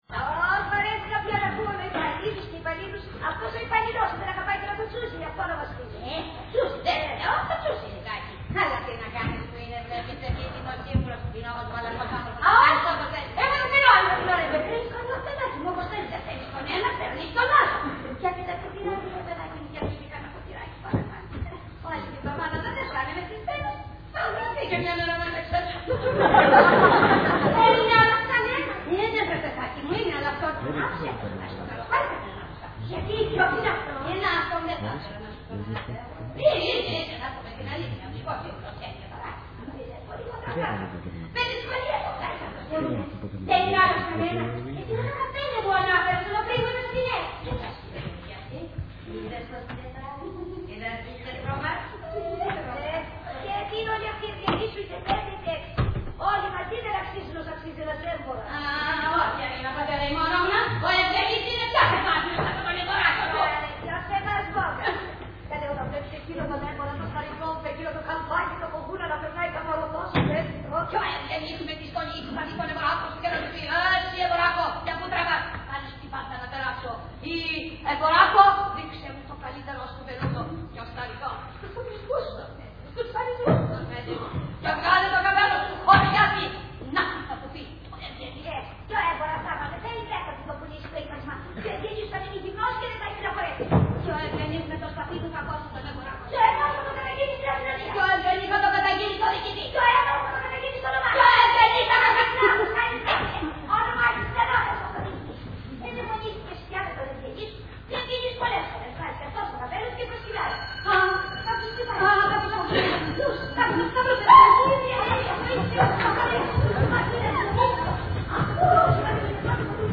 Ηχογράφηση Παράστασης
Αποσπάσματα από την παράσταση
sound δείγμα, διάρκεια 00:02:50, ΑΓΑΦΙΑ, ΦΕΚΛΑ, ΑΡΗΝΑ, ΝΤΟΥΝΙΑΣΣΑ